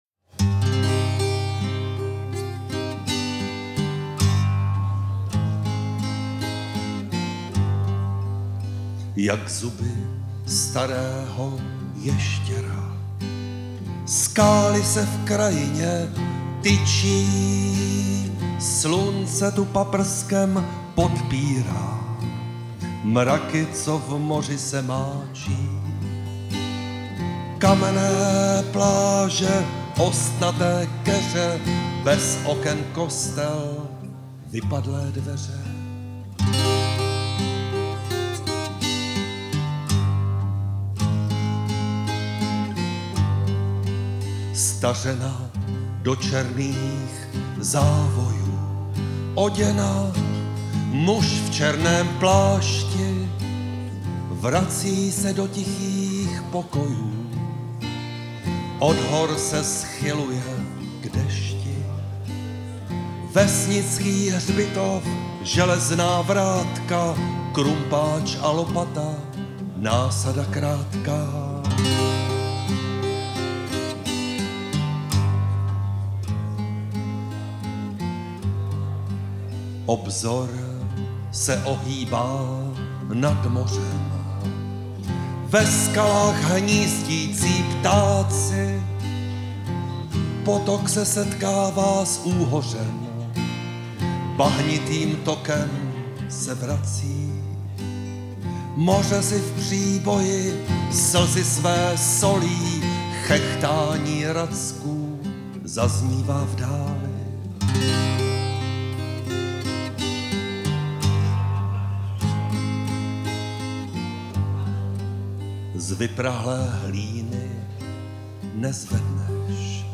Český písničkář, spisovatel, básník a fejetonista.